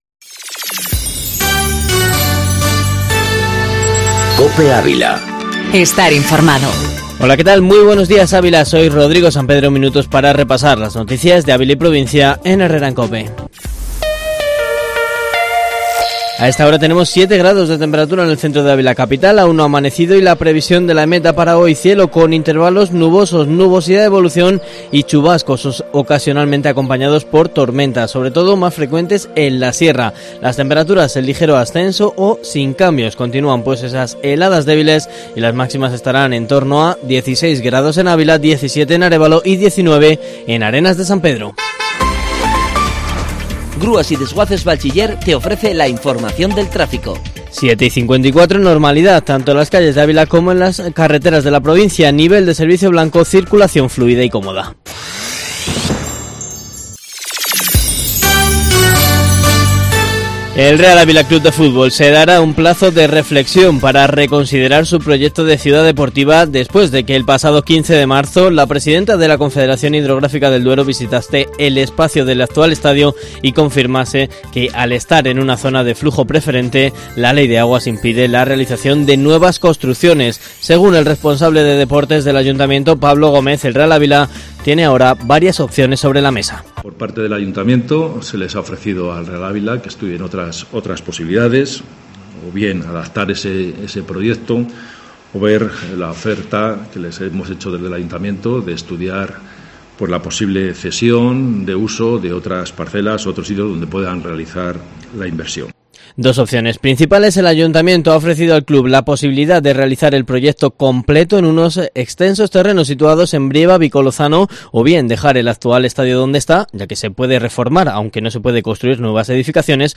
Informativo matinal Herrera en COPE Ávila 02/04/2019